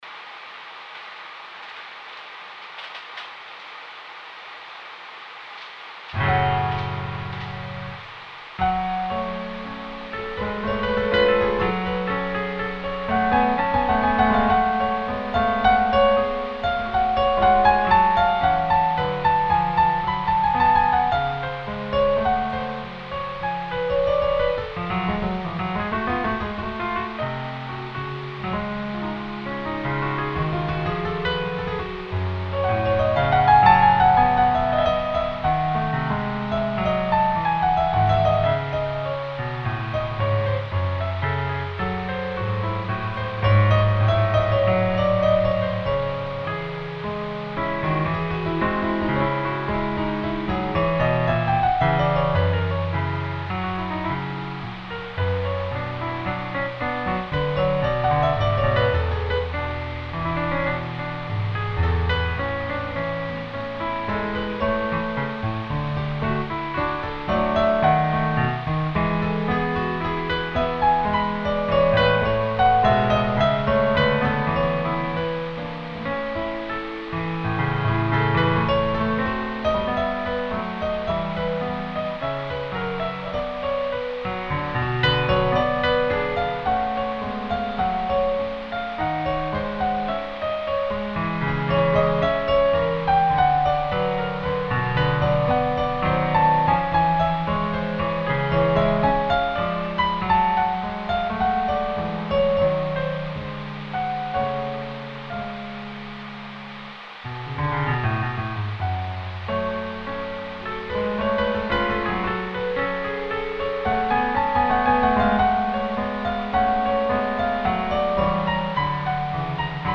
Just me noodling around with a Bach F-Sharp Minor Prelude from the WTC.
Aug 22, 2010 | Piano Music, Sounds